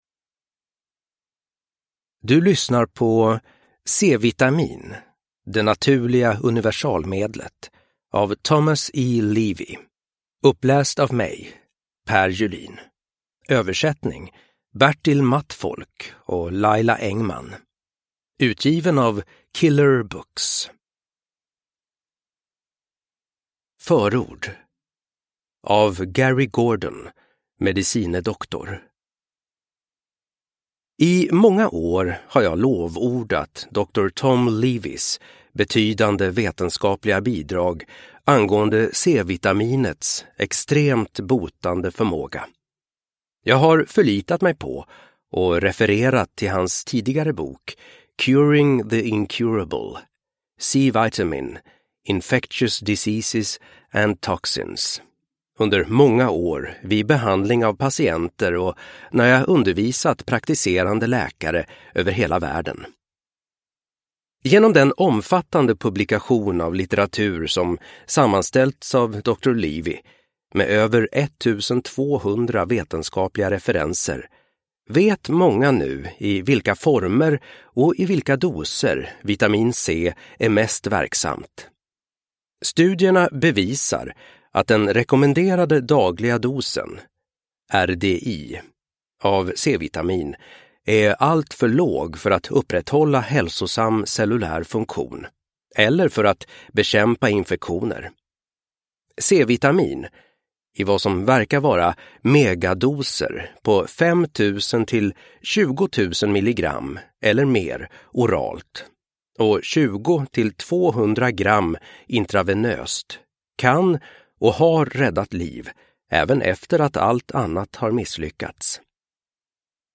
C-vitamin - Det naturliga universalmedlet – Ljudbok